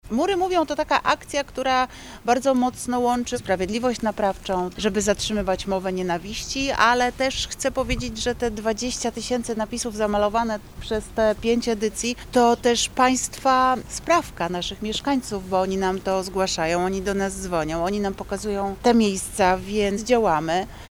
Każdego roku z wrocławskich ścian znika ponad 3 tysiące nienawistnych napisów, a dzięki akcji „Mury mówią” łącznie zniknęło ich już ponad 20 tysięcy, mówi Renata Granowska, wiceprezydent Wrocławia.